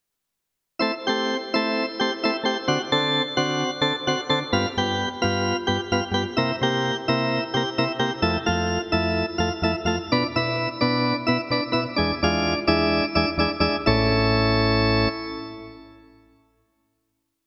12. I SUONI - GLI STRUMENTI XG - GRUPPO "ORGAN"